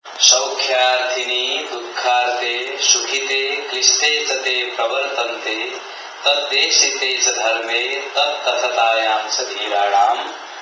āryā